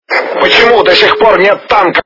При прослушивании Голос - Почему до сих пор нет танко качество понижено и присутствуют гудки.
Звук Голос - Почему до сих пор нет танко